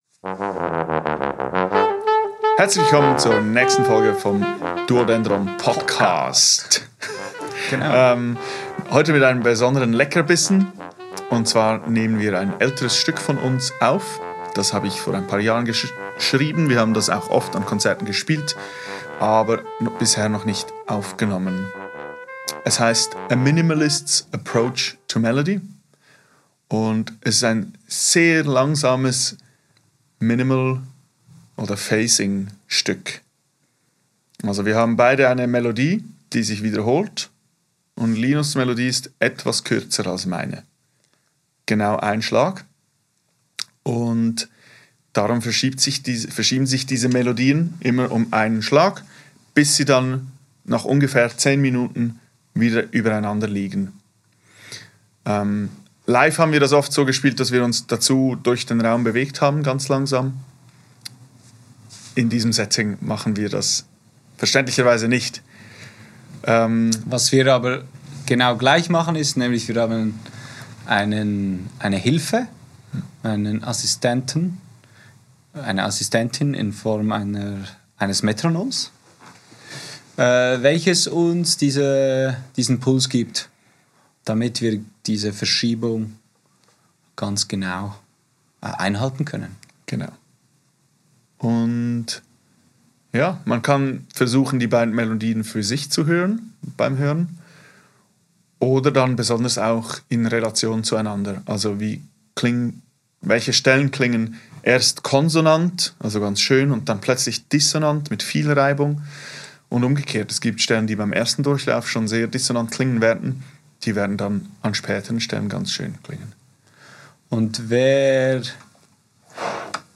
Aufgenommen am 17.04.2024 im Atelier